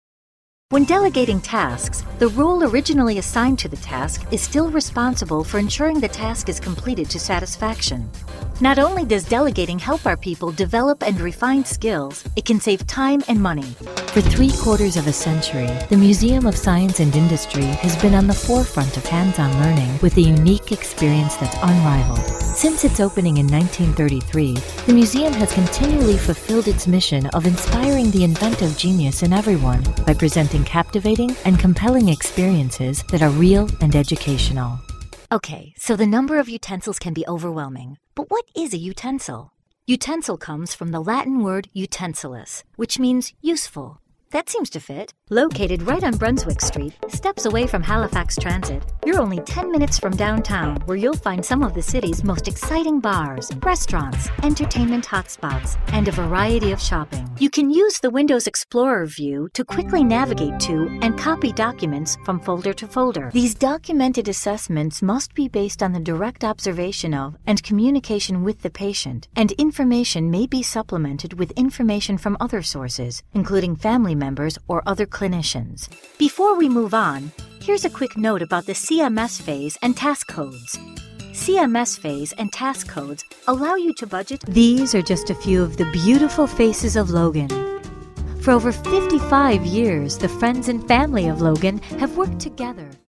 Clients love her genuine sound and accomplished acting abilities.
Sprechprobe: Industrie (Muttersprache):